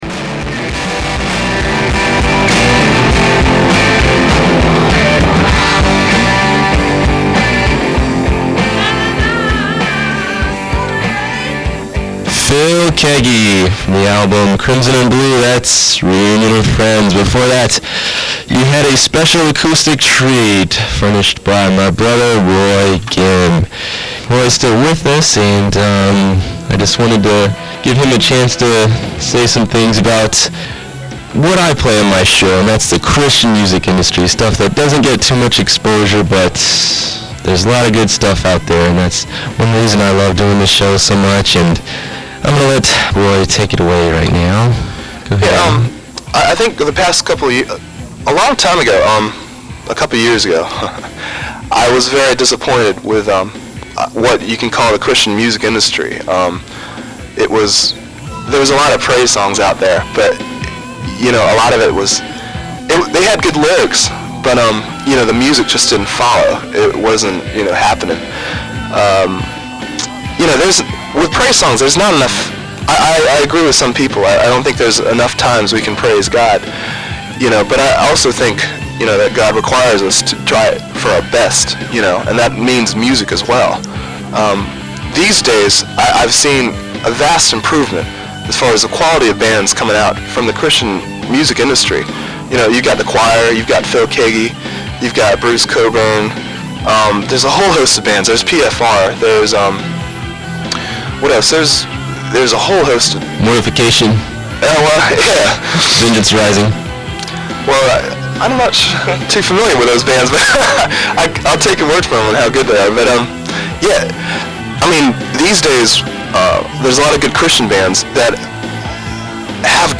Live in studio guest performances